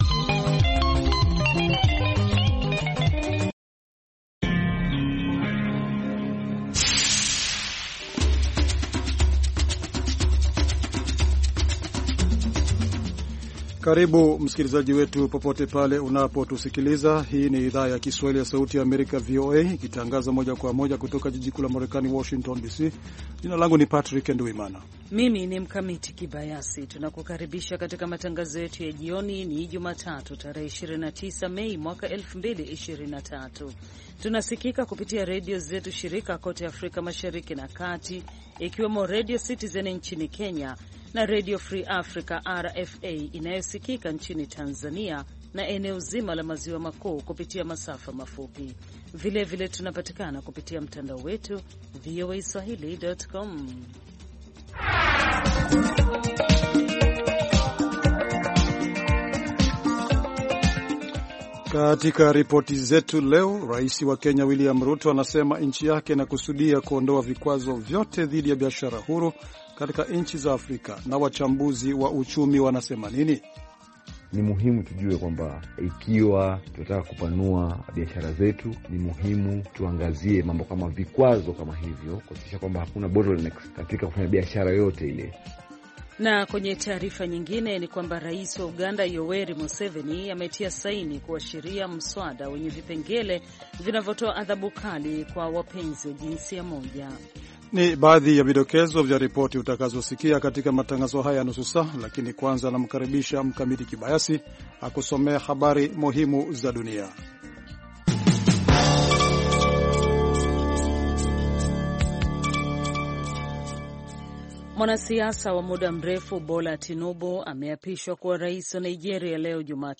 Matangazo ya saa nzima kuhusu habari za kutwa, ikiwa ni pamoja ripoti kutoka kwa waandishi wetu sehemu mbali mbali duniani na kote Afrika Mashariki na Kati, na vile vile vipindi na makala maalum kuhusu afya, wanawake, jamii na maendeleo.